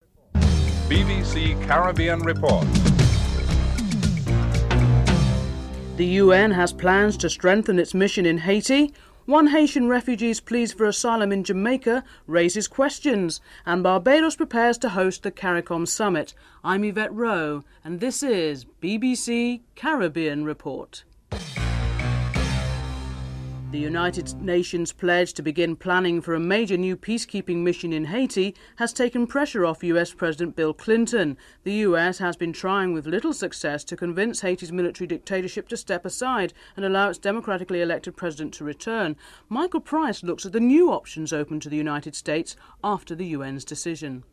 7. In cricket news, Warwickshire says that Brian Lara will miss a cricket match due to a knee ligament damage.